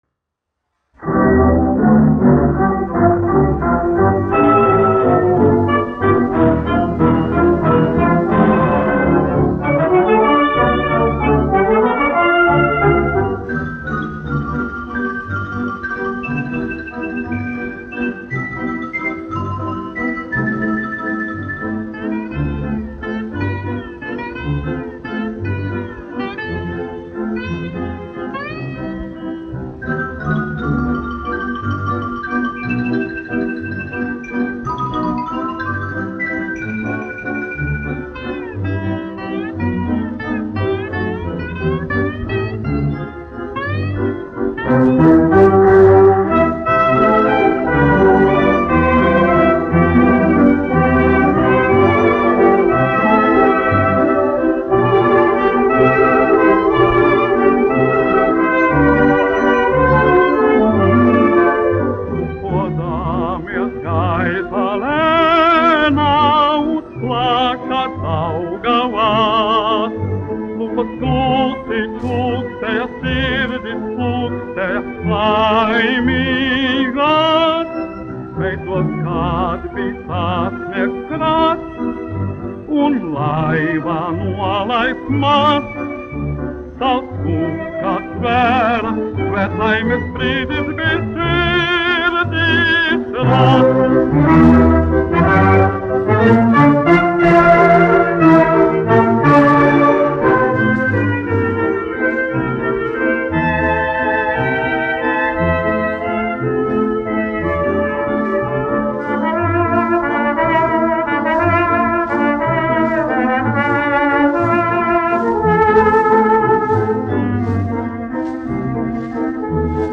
1 skpl. : analogs, 78 apgr/min, mono ; 25 cm
Populārā mūzika
Skaņuplate
Latvijas vēsturiskie šellaka skaņuplašu ieraksti (Kolekcija)